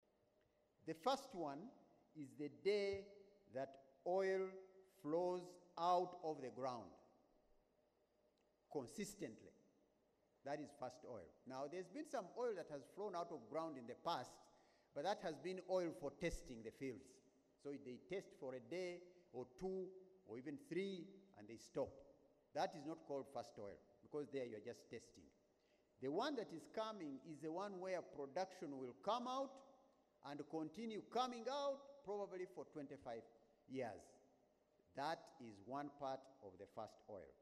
Speaking at the opening of the 6th Annual National Content Conference in Kampala, Rubondo explained that although commercial oil production from the Kingfisher and Tilenga projects is scheduled to begin mid-2026, this does not mean the government will earn money immediately.
Rubondo speaking on first oil